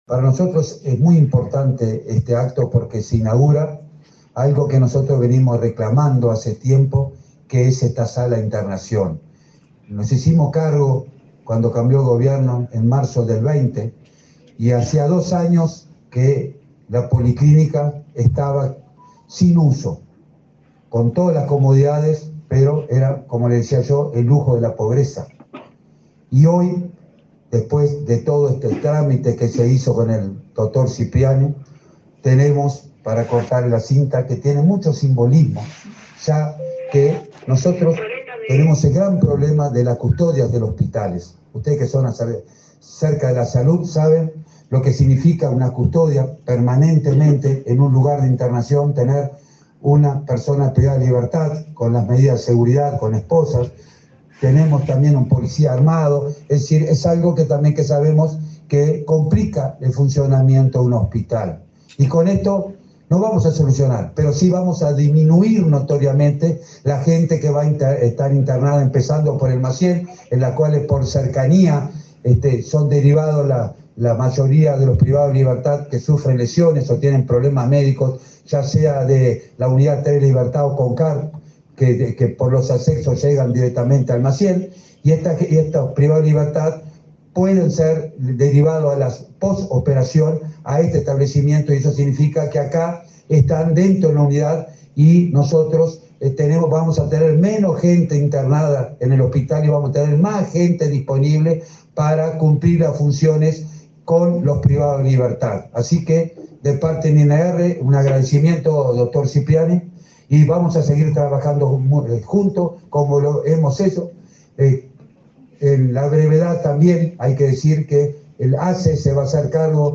Palabras de autoridades del Ministerio del Interior y ASSE
El Ministerio del Interior y la Administración de los Servicios de Salud del Estado (ASSE) inauguraron, este martes 13, la sala de cuidados y rehabilitación del Instituto Nacional de Rehabilitación (INR) y el Sistema de Atención Integral de las Personas Privadas de Libertad, en la Unidad n.° 1. El director del INR, Luis Mendoza, y el presidente de ASSE, Leonardo Cipriani, se expresaron acerca de la importancia del nuevo servicio.